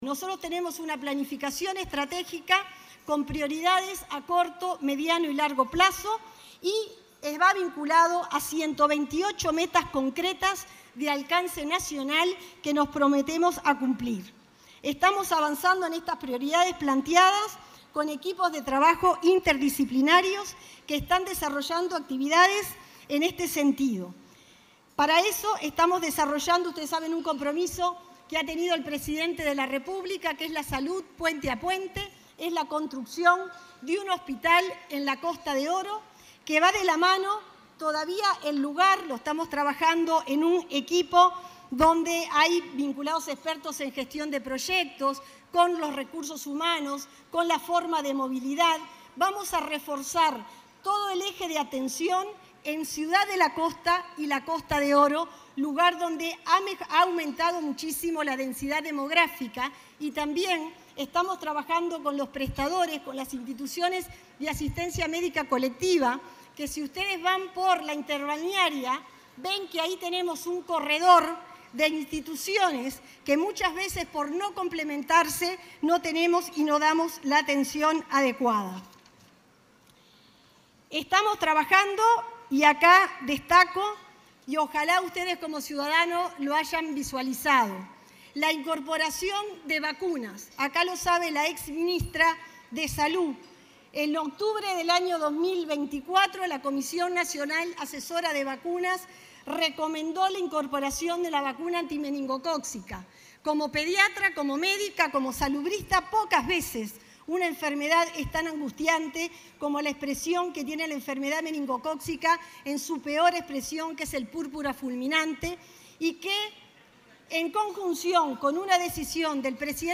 Palabras de la ministra de Salud Pública, Cristina Lustemberg, en ADM | Presidencia Uruguay
La titular del Ministerio de Salud Pública, Cristina Lustemberg, disertó, en un almuerzo de trabajo, acerca de los desafíos de la cartera en esta